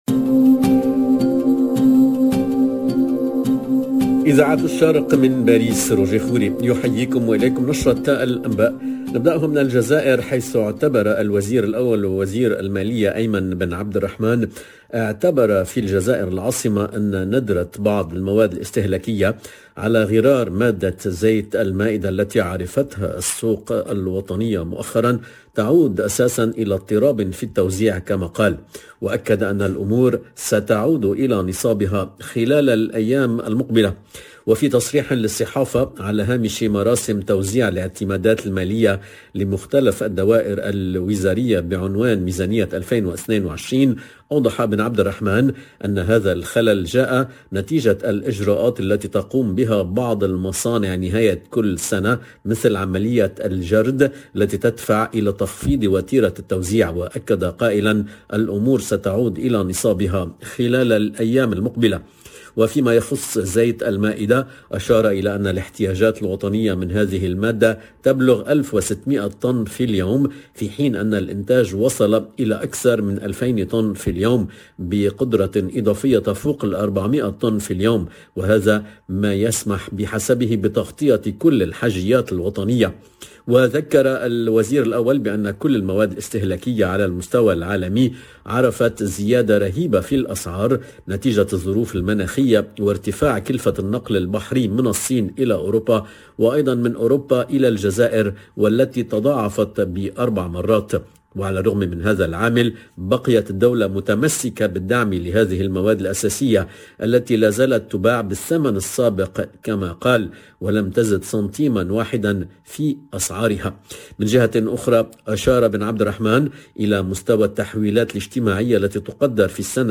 LE JOURNAL DE 13h30 EN LANGUE ARABE DU 7/01/22